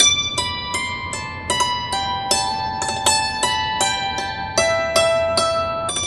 Dulcimer10_79_G.wav